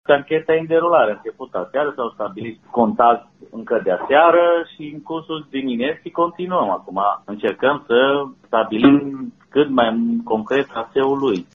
Revine directorul DSP Mureș, dr. Iuliu Moldovan: